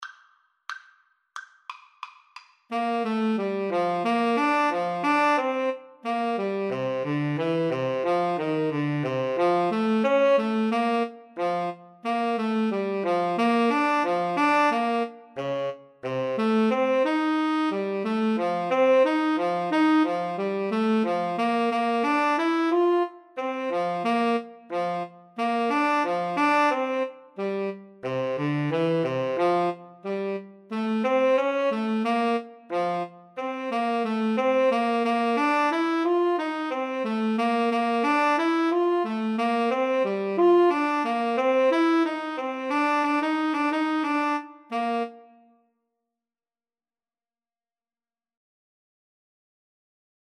Moderato = c.90
2/2 (View more 2/2 Music)